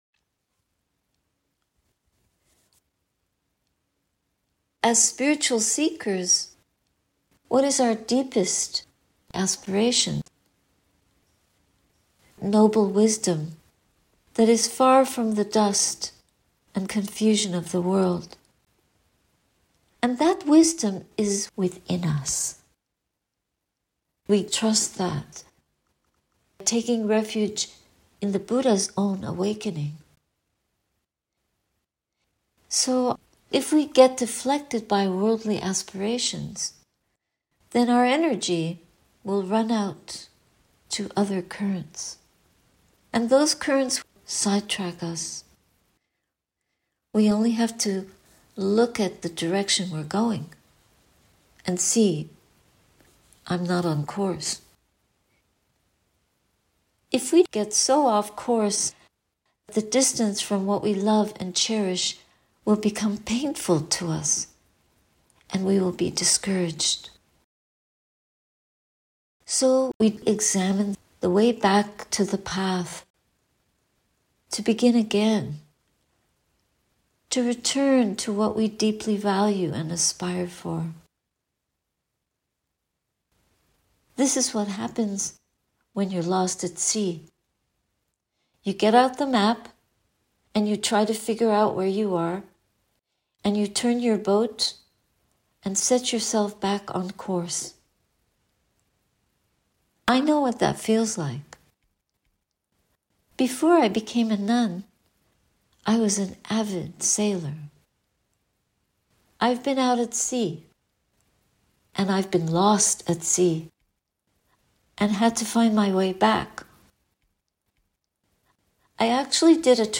A talk given during Satipaññā Insight Meditation Toronto retreat in 2015.